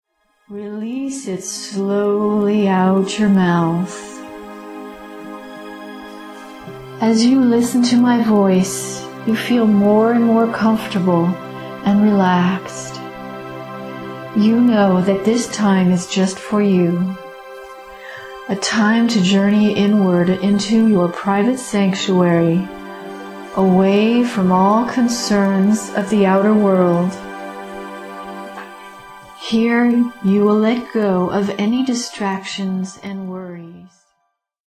This guided hypnosis session guides you to a deep state of altered consciousness. This session creates the space for you to find your answers within.